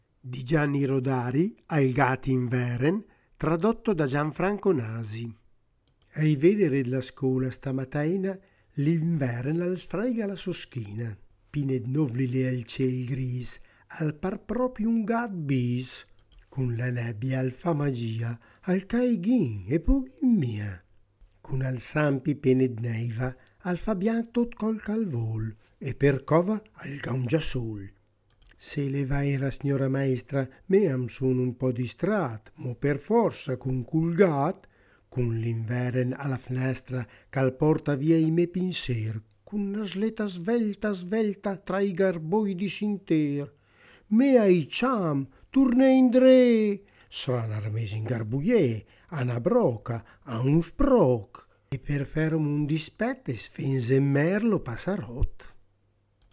Traduzione e lettura